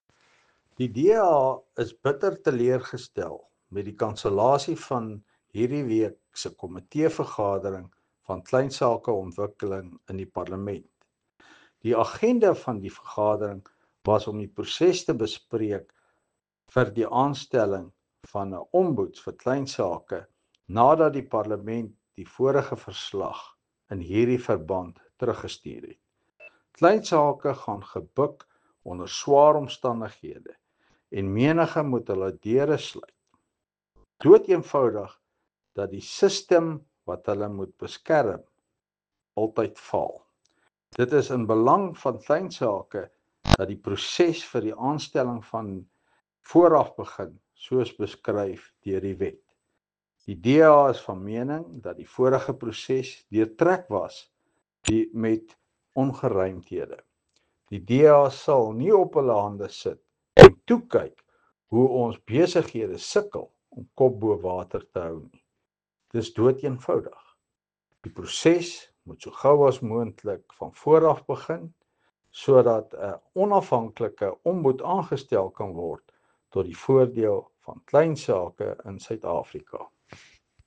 Afrikaans soundbite by Henro Kruger MP.